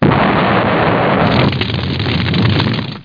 CrashR.mp3